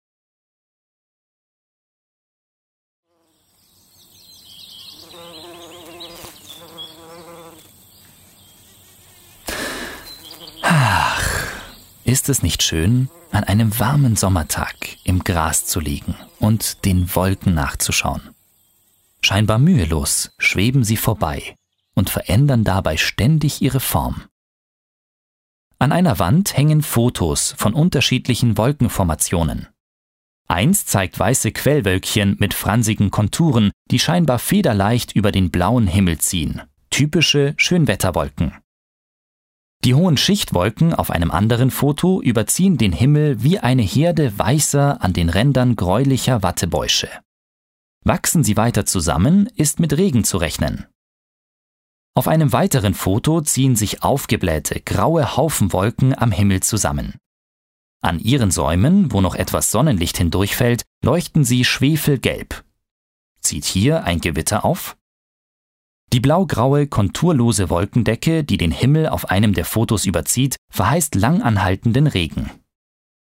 Sprecher Audioguide | Wasser Bewegt
...meine Rolle im Audioguide bestand darin, die dämlichen Fragen zu stellen.